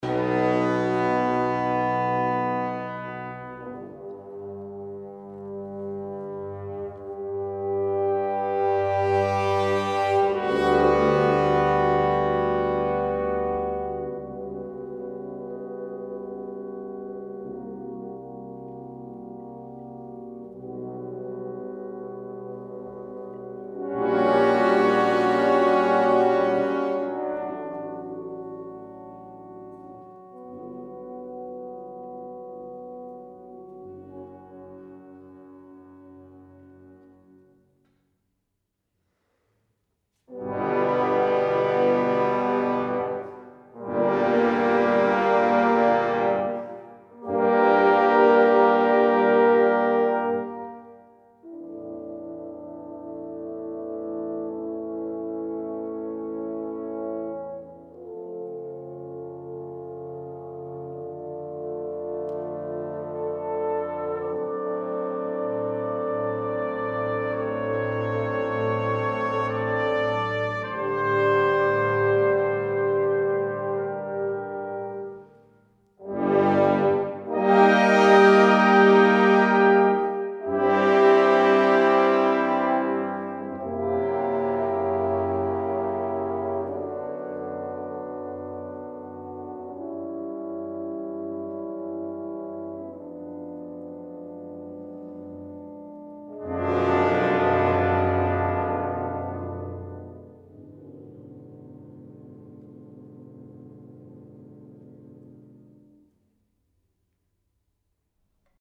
Hör dir die Musik „Bilder einer Ausstellung“ von Modest Mussorgsky, gespielt von der Philharmonie Salzburg an.